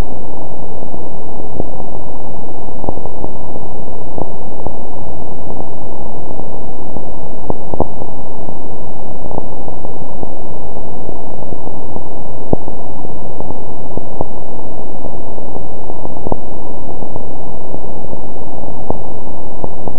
pulsar du crabe (période de rotation 33 ms) comme sur la page d'accueil!!!